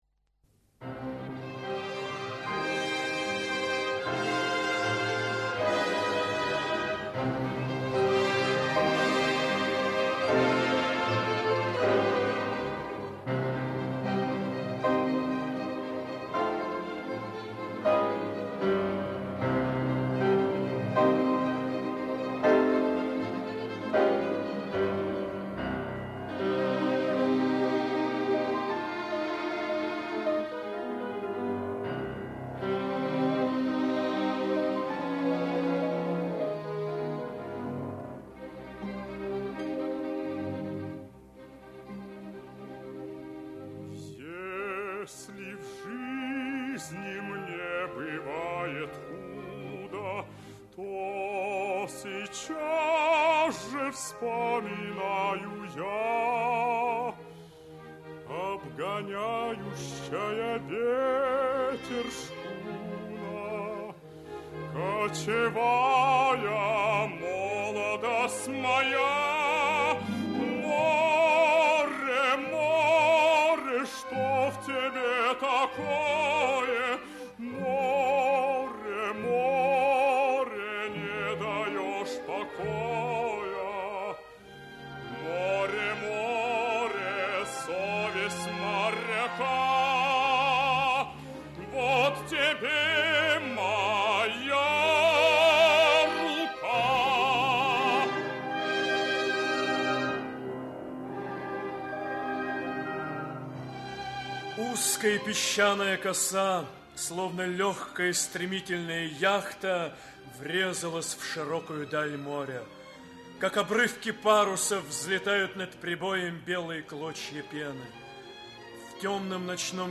Аудиокнига Обгоняющая ветер | Библиотека аудиокниг
Aудиокнига Обгоняющая ветер Автор Константин Игнатьевич Кудиевский Читает аудиокнигу Актерский коллектив.